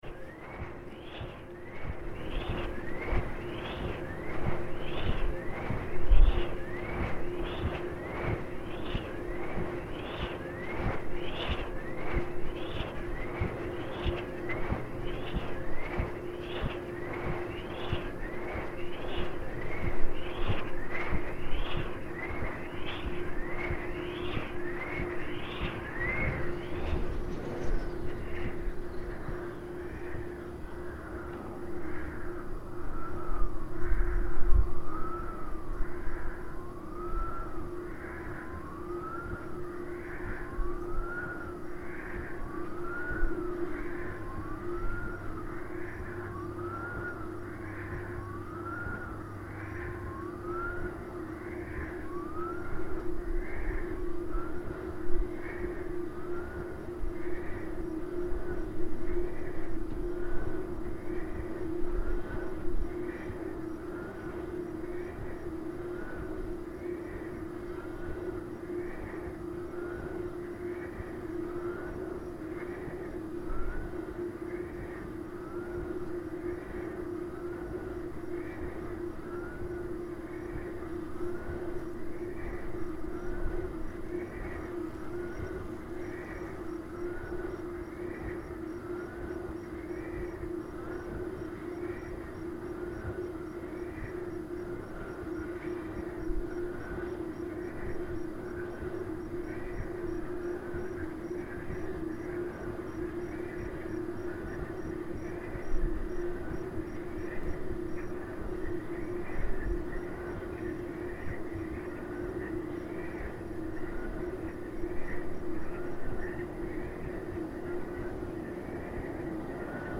Wind turbine on Ningaloo Coast
This is an incredibly eerie recording of the wind turbine located on Ningaloo Coast to provide power for Coral Bay. It is in an area out of town in the desert where you can find lizards and birds.